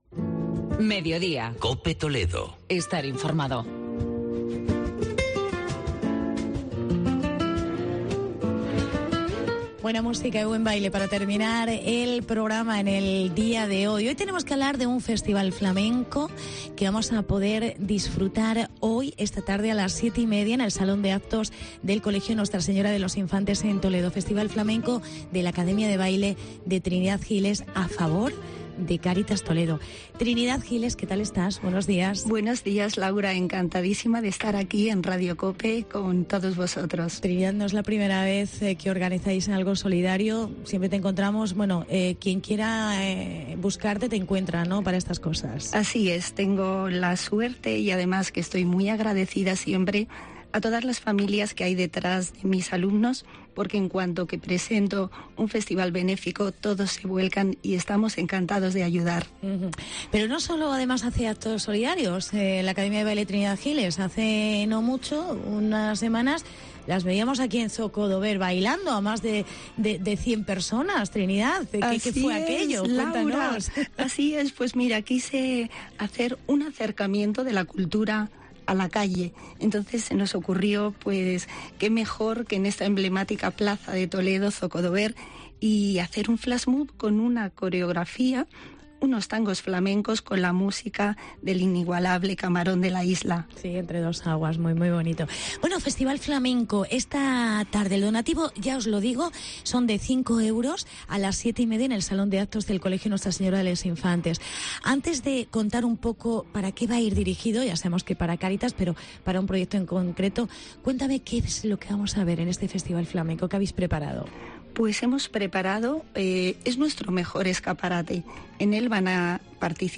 Festival de Flamenco a favor de Cáritas. Entrevista